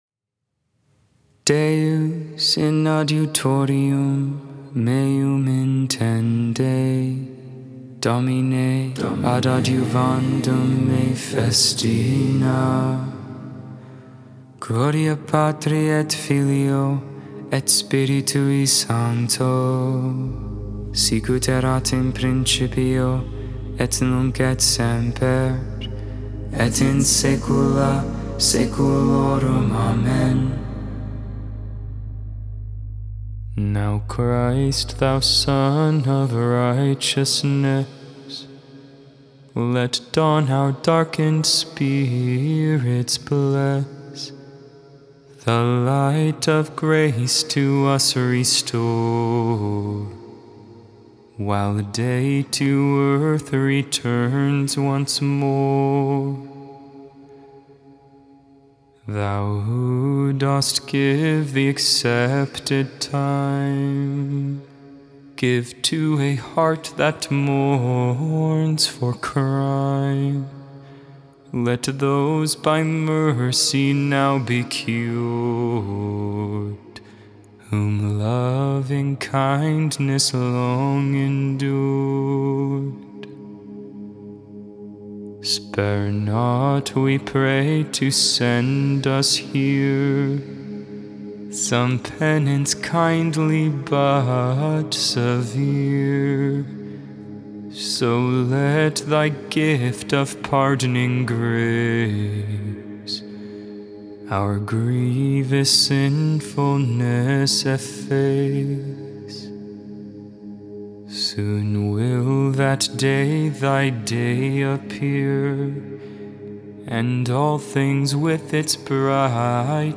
10th century Lenten Hymn
Psalm 51 (tone 7)